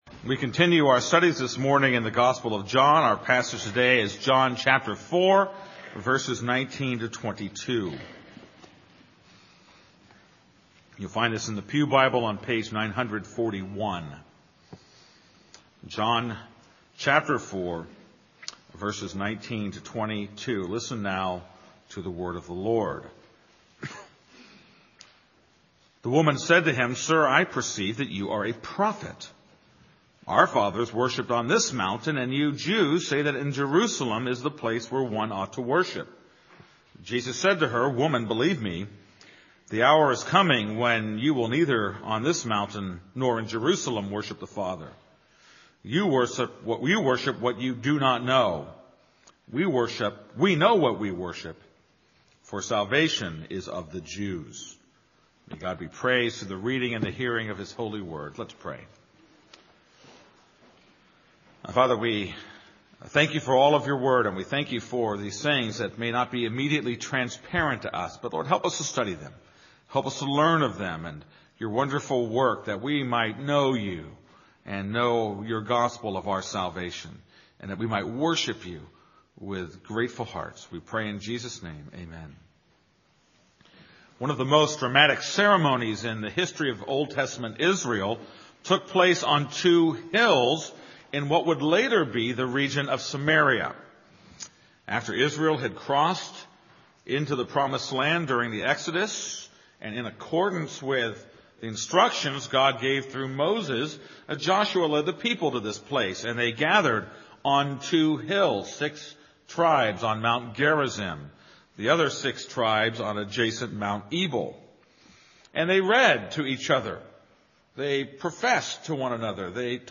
This is a sermon on John 4:19-22.